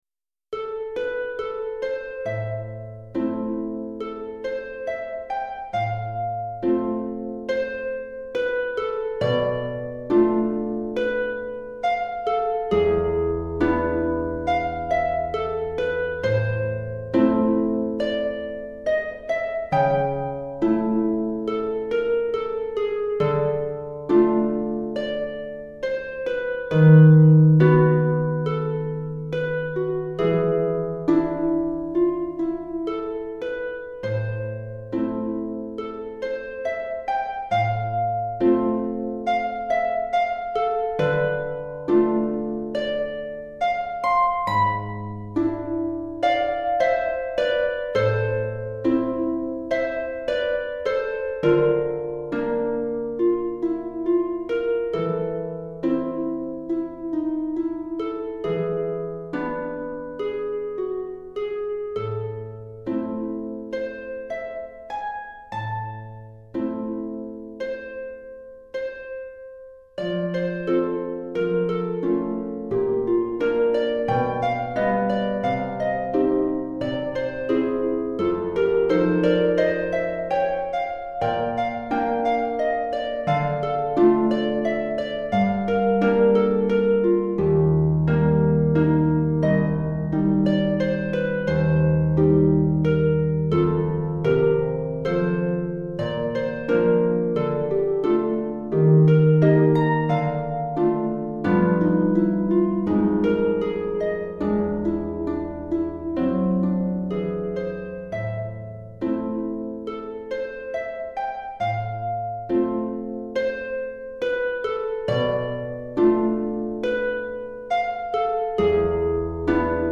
Pour harpe DEGRE CYCLE 1